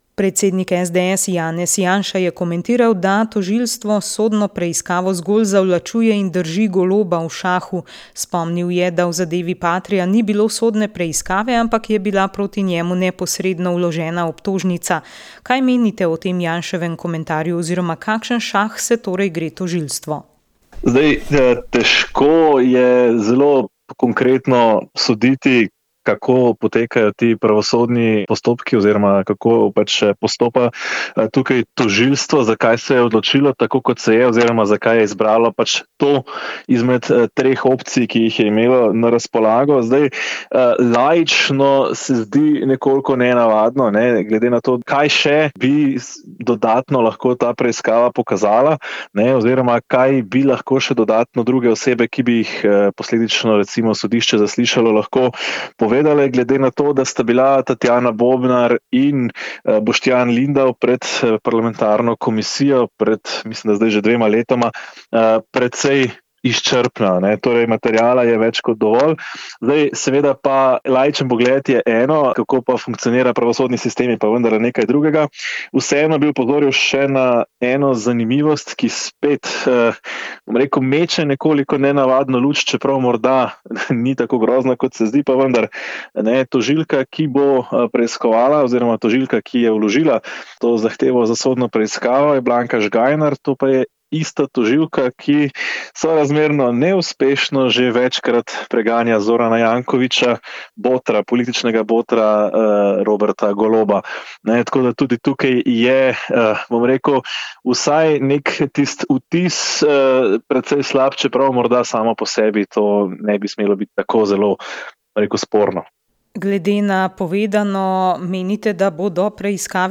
Ustanovila sta jih sveti Vincencij Pavelski in sveta Ludovika de Marillac, v Slovenijo pa jih je pripeljala sestra Leopoldina Jožefa Brandis. Praznovanje je potekalo pri Svetem Jožefu v Celju.